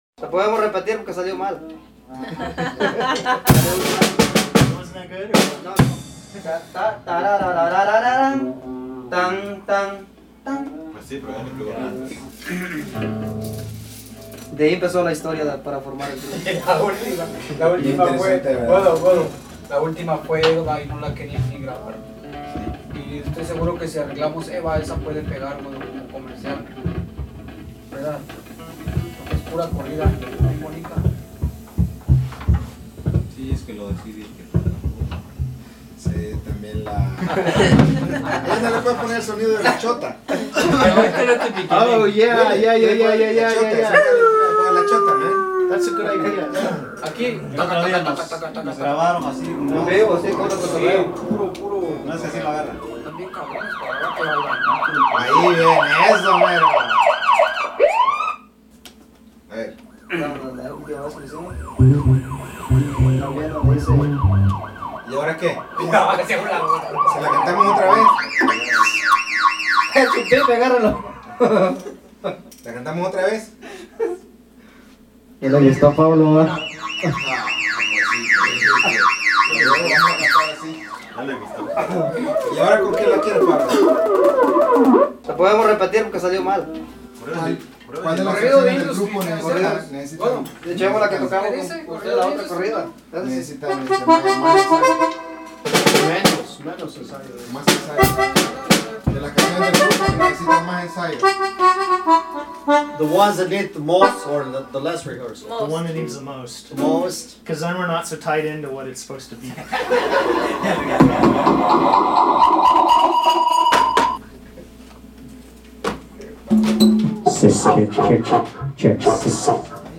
minimalismo
electronic norteña